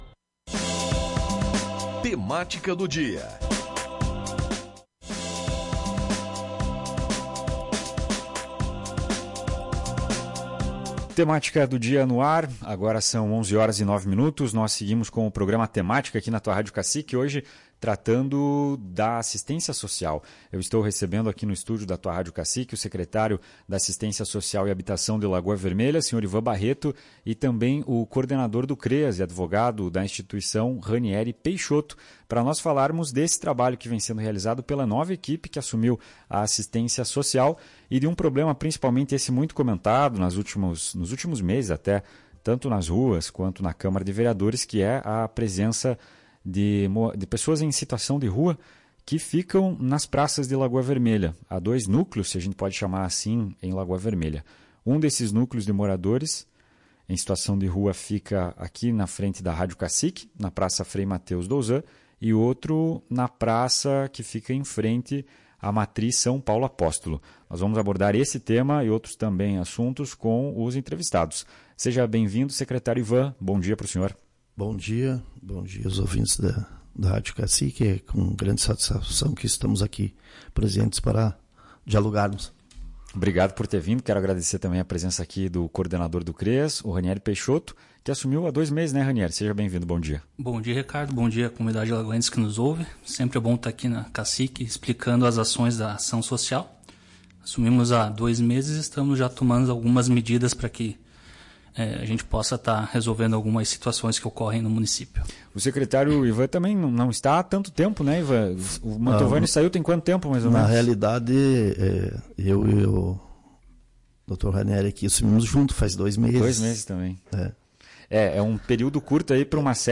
O município de Lagoa Vermelha tem enfrentado nos últimos meses um aumento na presença de pessoas em situação de rua.